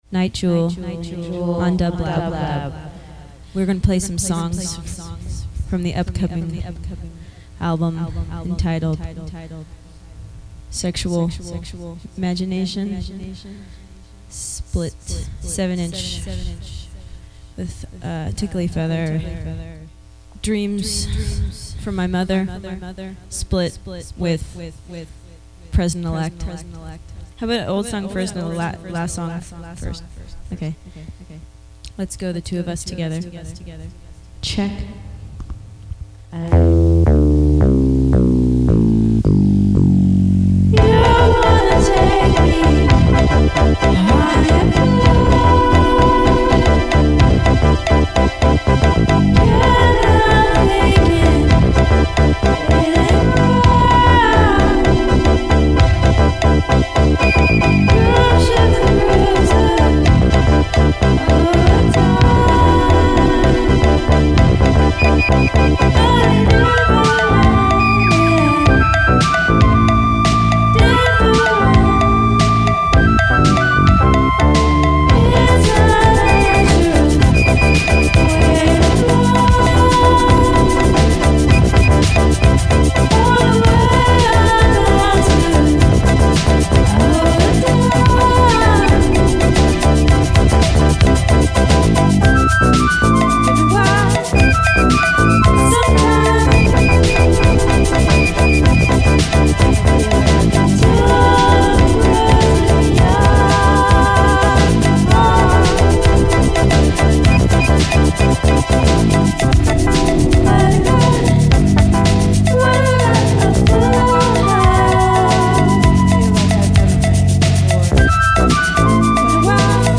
Disco/House Electronic Live Performance Pop